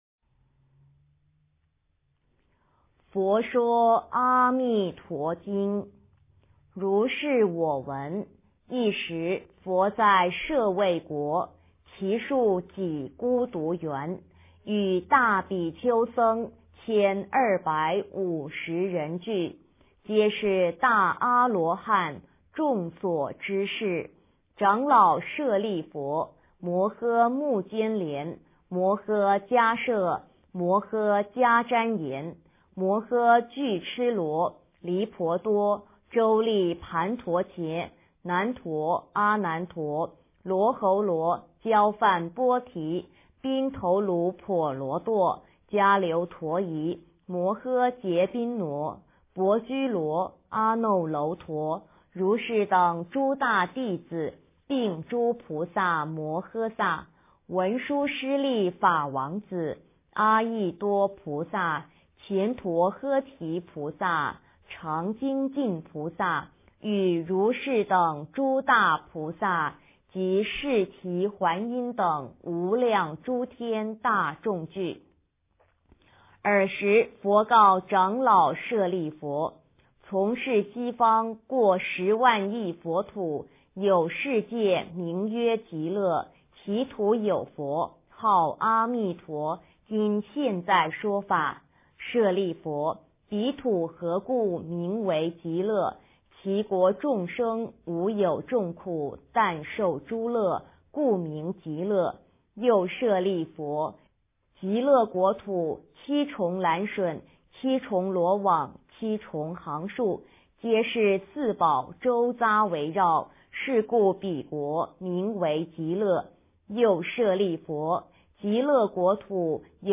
佛说阿弥陀经 - 诵经 - 云佛论坛